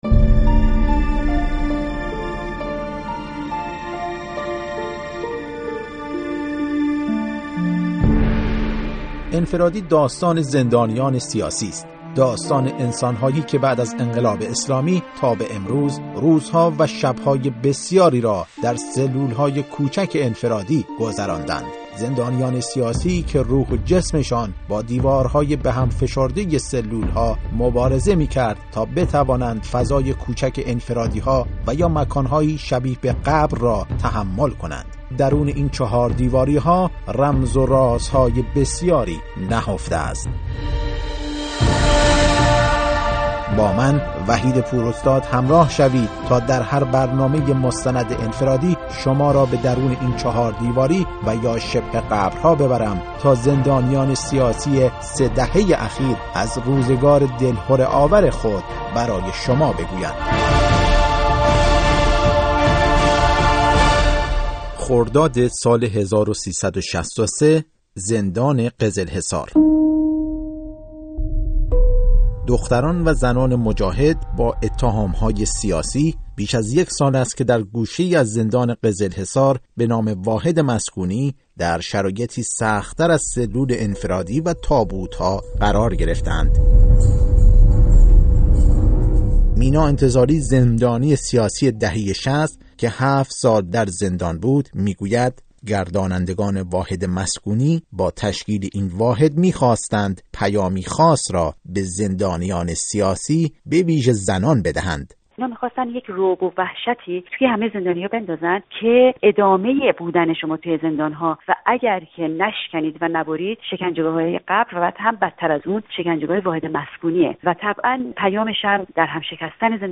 در بخش «هشتم» برنامه مستند رادیویی «انفرادی» و در ادامه بخش قبلی شما را به «واحد مسکونی» می‌بریم